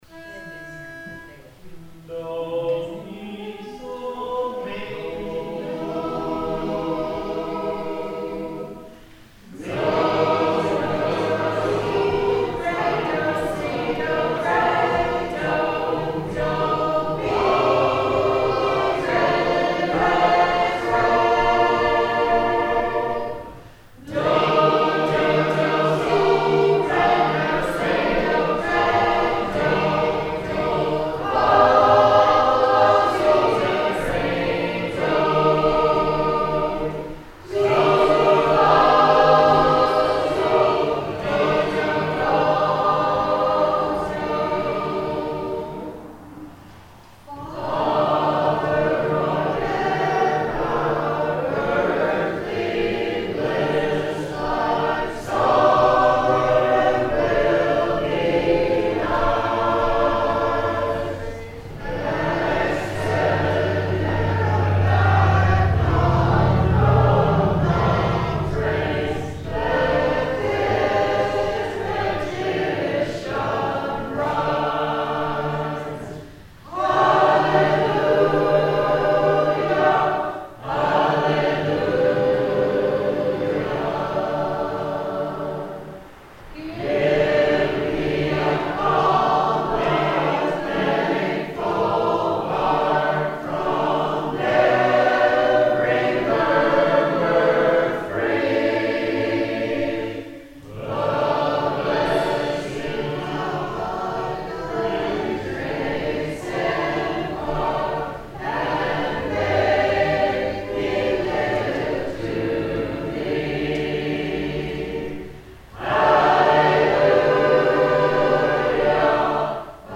Headricks Chapel – September 22, 2013 | Southern Field Recordings
Headrick’s Chapel – New Harp of Columbia, 09/22/2013
There might not be a better church to sing in than Headrick’s Chapel – the acoustics are phenomenal, and the setting in the foothills of the Smoky Mountains is breathtaking. Unfortunately, the church is about five feet from US 321 (the highway from Pigeon Forge to Townsend), so we have to compete with the motorcycles…